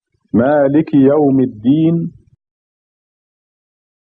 You can Listen to each verse of The Key (Al-Fatehah) being recited by clicking the "Listen" link next to the verse.